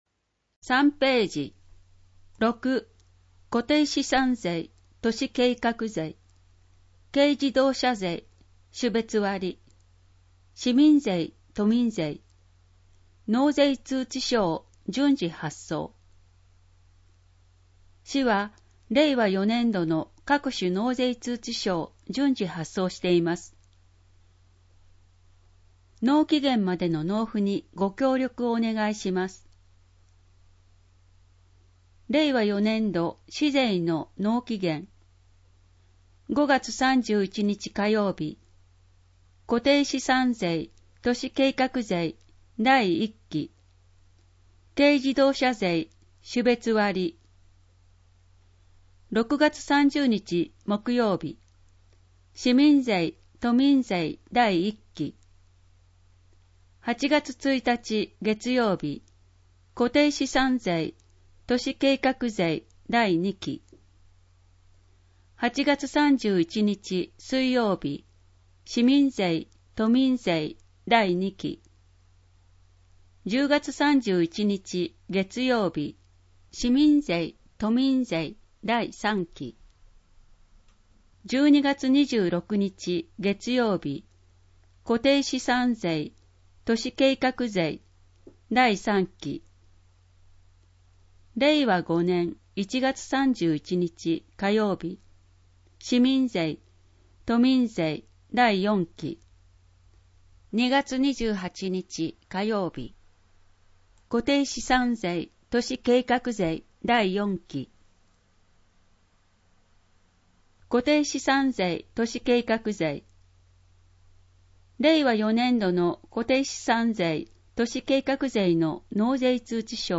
MP3版（声の広報）